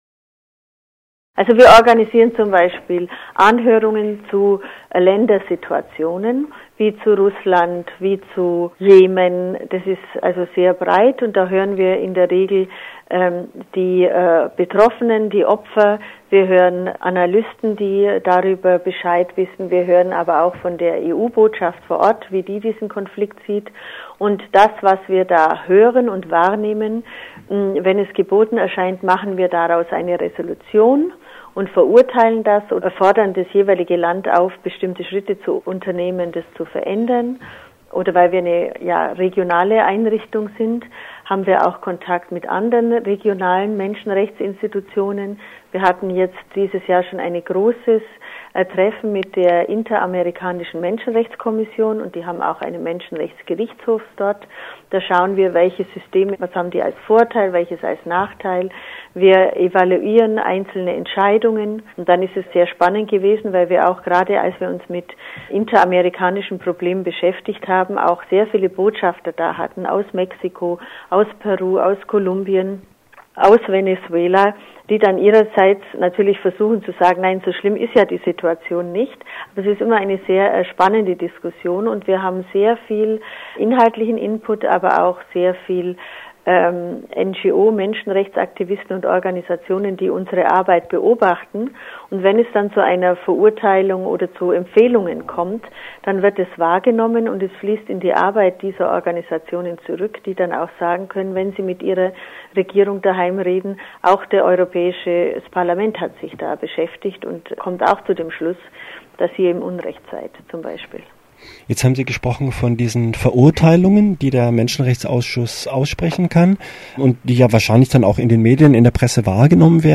Focus Europa hat ein Interview geführt mit der Abgeordneten Barbara Lochbihler von der Fraktion der Grünen, die derzeit die Vorsitzende dieses Menschenrechtsausschusses ist.